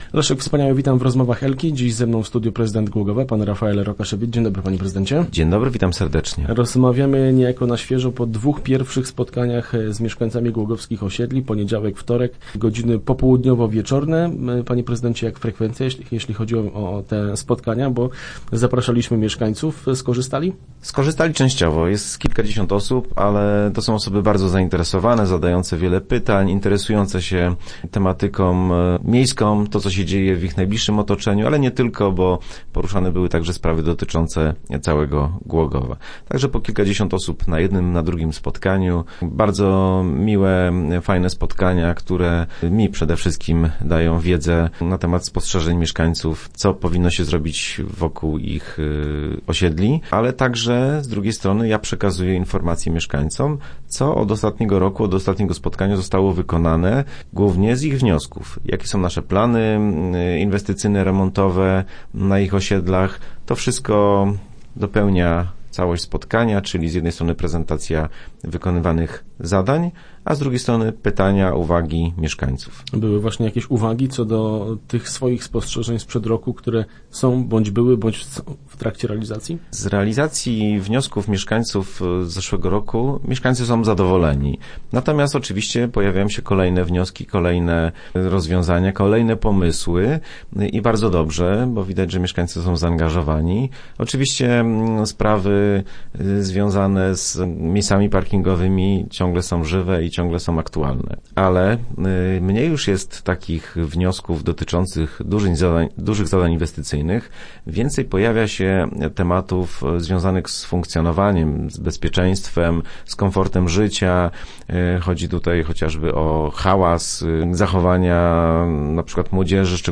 Start arrow Rozmowy Elki arrow Mieszkańcy są zaangażowani
W środę w radiowym studiu Rafael Rokaszewicz podsumował pierwsze dwa spotkania.